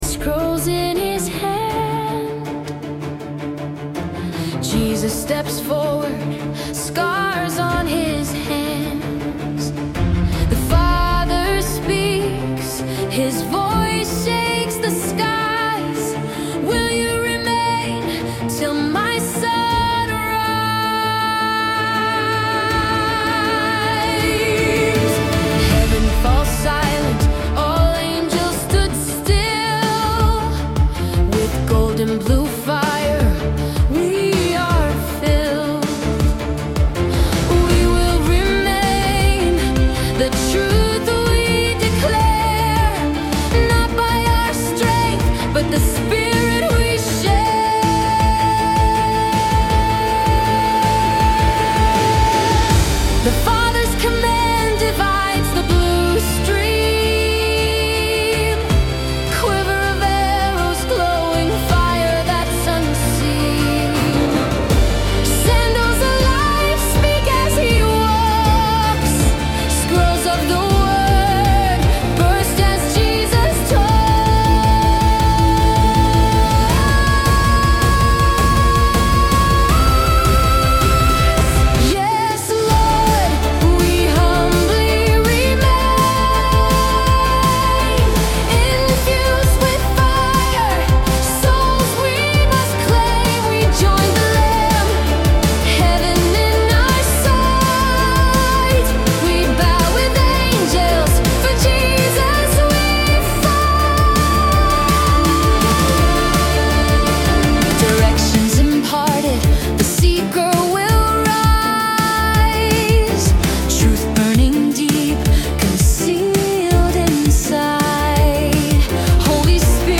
soaring Christian rock anthem
• Genre: Christian Rock / Worship Rock